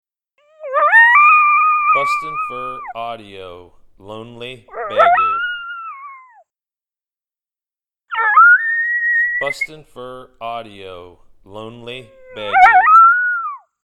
MotoMoto lone howling, looking for communication from a pack mate. Excellent stand starter howl.
• Product Code: howls